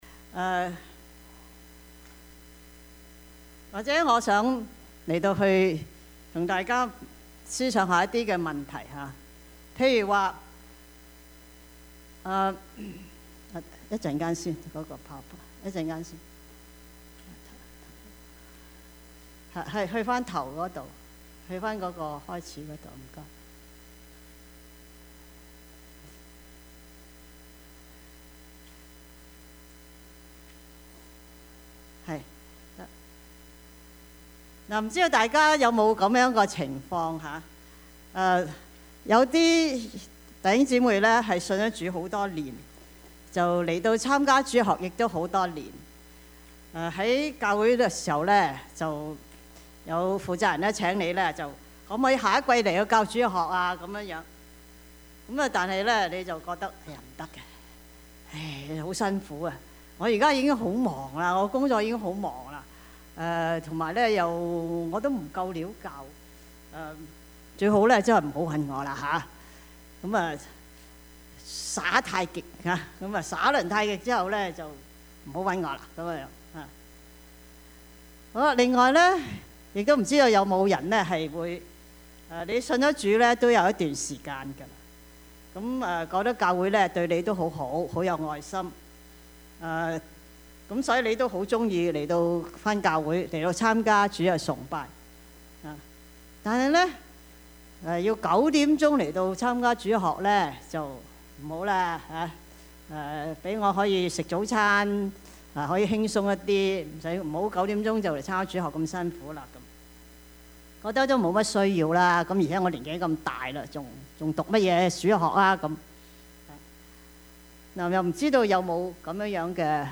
Passage: 以弗所書 4:11-16 Service Type: 主日崇拜
Topics: 主日證道 « 我信身體復活; 我信永生 教會是我家 »